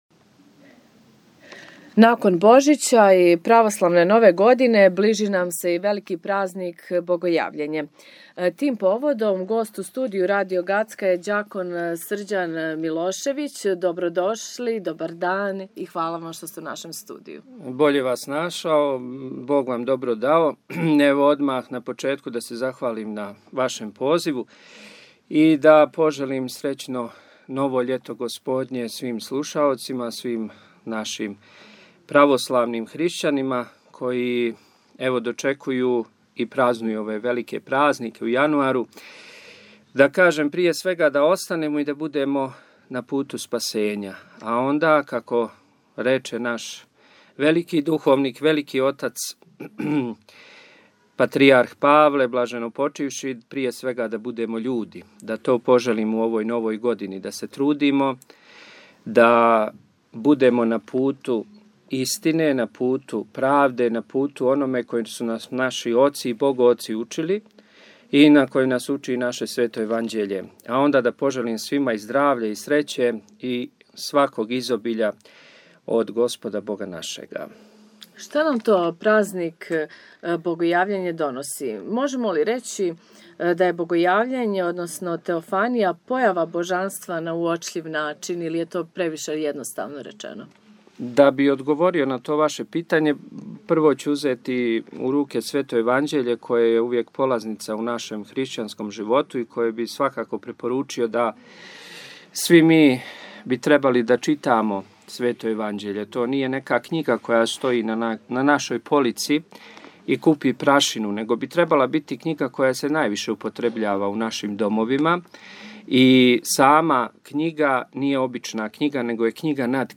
Kompletan razgovor slušate danas u našem programu od 13 časova ili na sajtu Radio Gacka. https